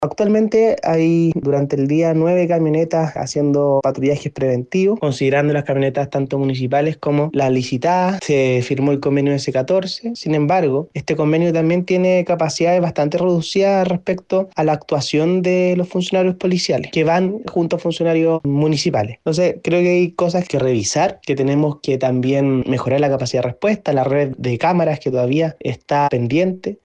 En tanto, el concejal Ignacio Navarro recalcó que, en materia de seguridad, es necesario evaluar distintos elementos, como la capacidad de respuesta y el fortalecimiento de la red de cámaras de vigilancia, actualmente pendiente.